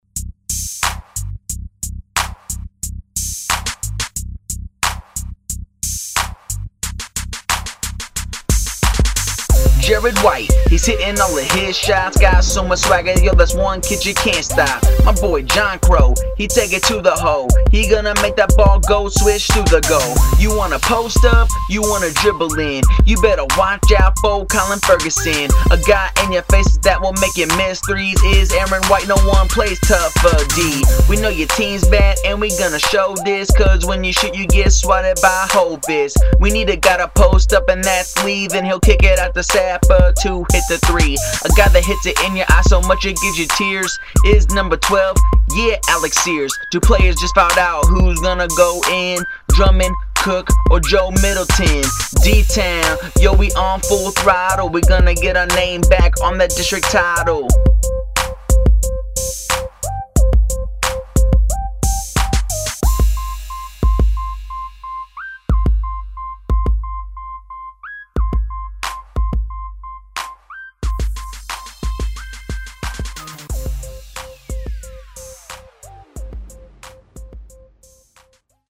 DeSoto Basketball Rap 09-10